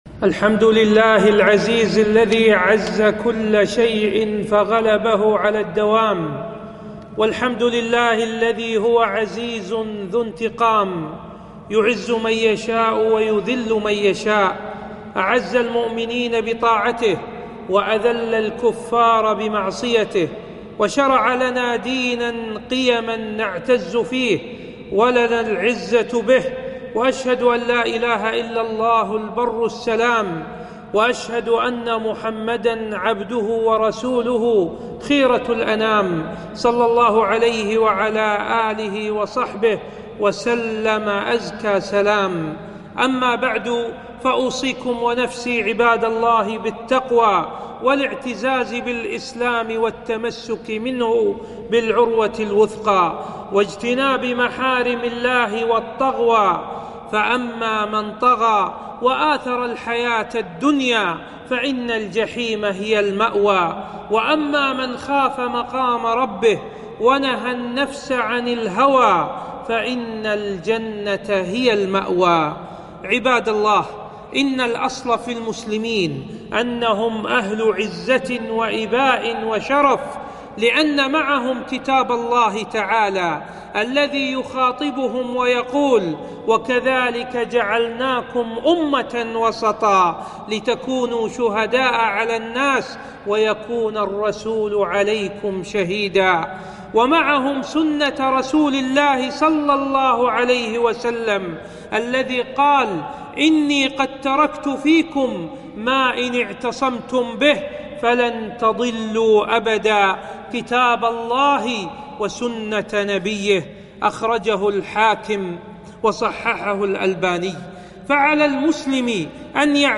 خطبة - عِـزَّةُ الإســـلام